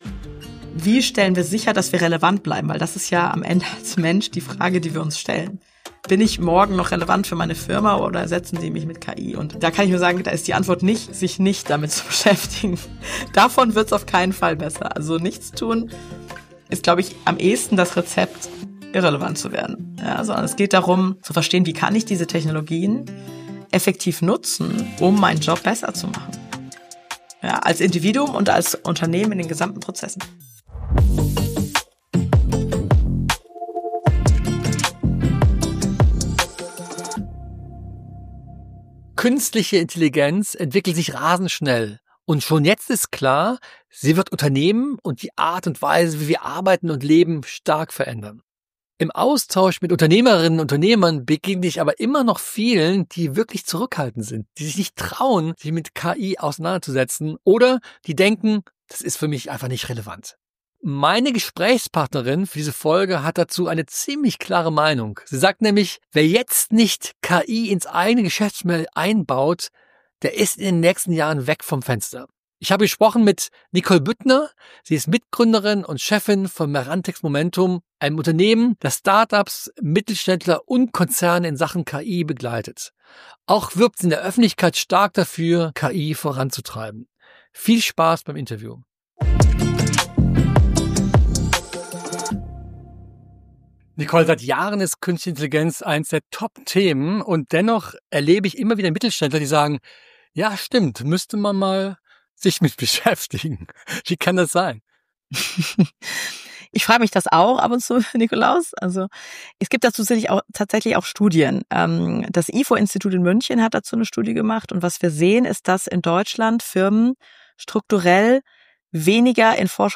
Im Interview erklärt sie, welche ersten Schritte Unternehmen jetzt gehen sollten und wie Führungskräfte auch das Team mitnehmen.